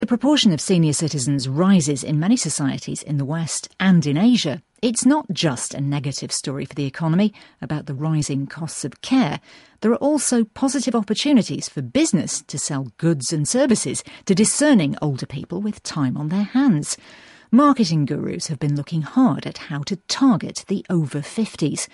【英音模仿秀】年老非衰微 经济亦发烧 听力文件下载—在线英语听力室